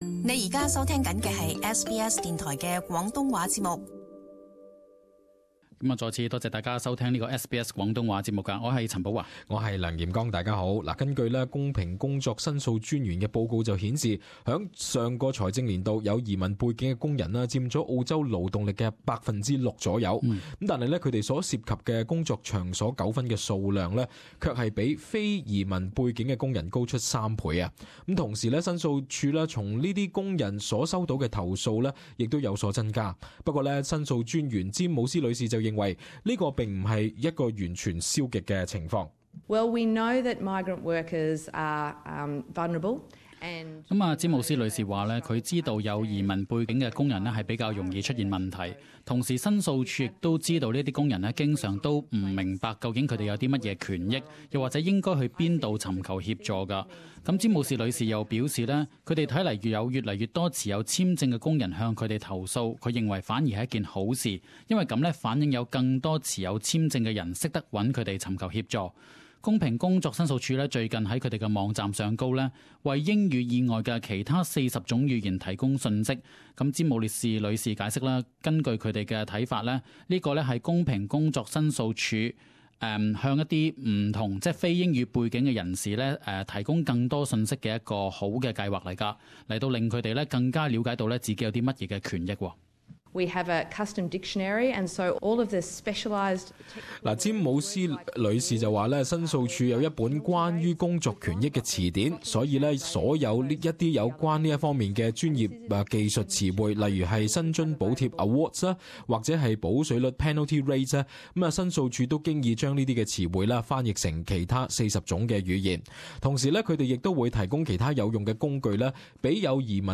【時事報導】工作權利與義務語言服務